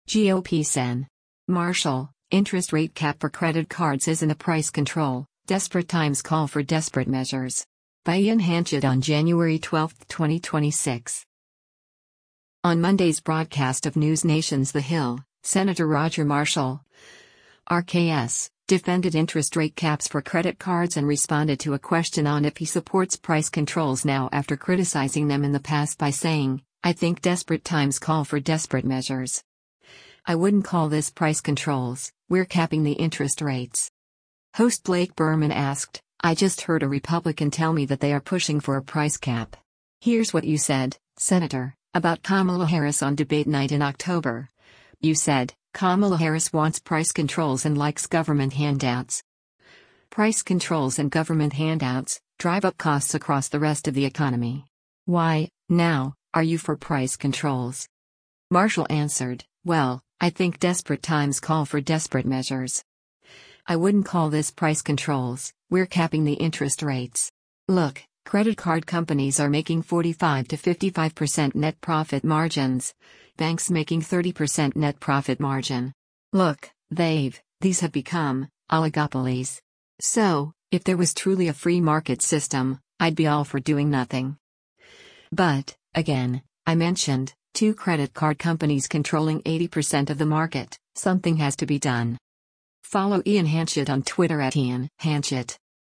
On Monday’s broadcast of NewsNation’s “The Hill,” Sen. Roger Marshall (R-KS) defended interest rate caps for credit cards and responded to a question on if he supports price controls now after criticizing them in the past by saying, “I think desperate times call for desperate measures. I wouldn’t call this price controls, we’re capping the interest rates.”